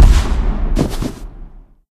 potatomine.ogg